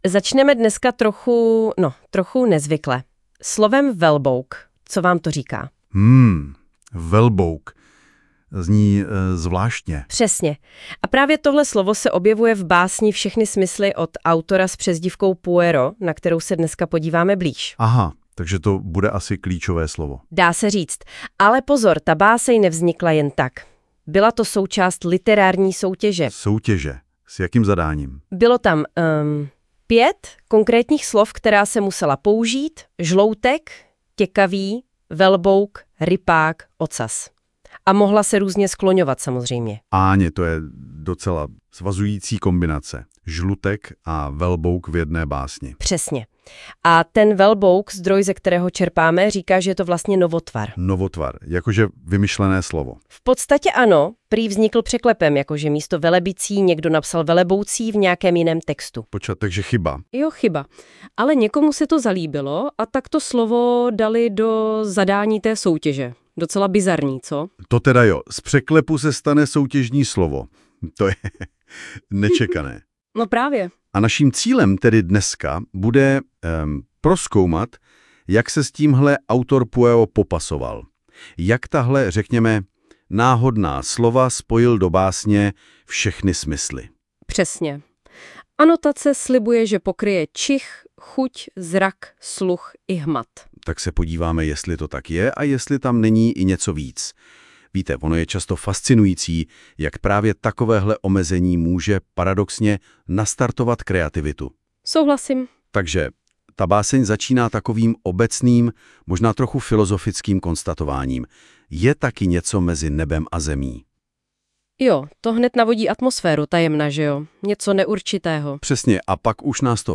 Rozhovor o vítězném díle z tématické soutěže.
Komplet vygenerováno přes AI.